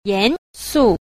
7. 嚴肅 – yánsù – nghiêm túc
yan_su.mp3